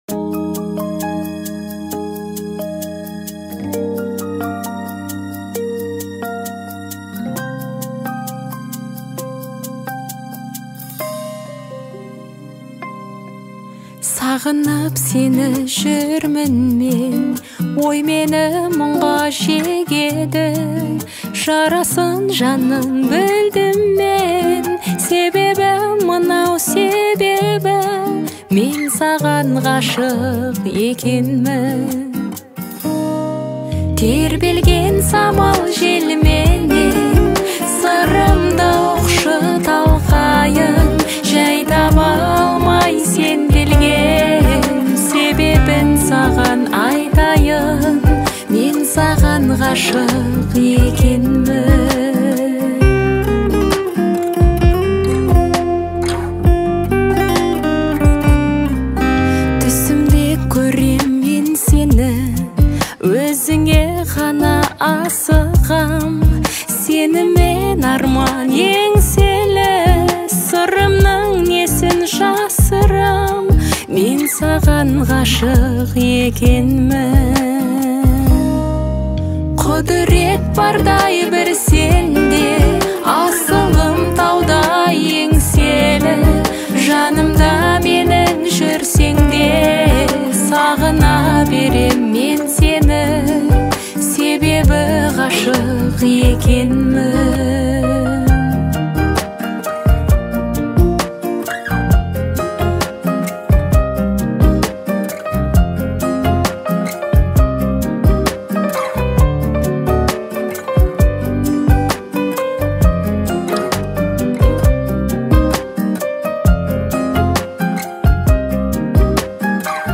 • Категория: Казахские песни /